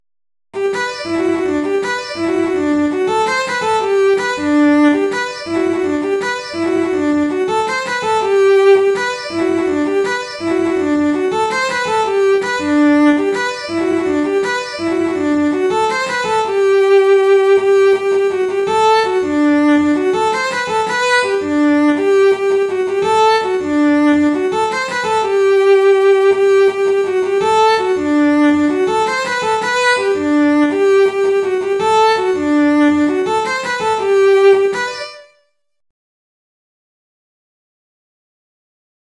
GAITA MUSIC ARCHIVE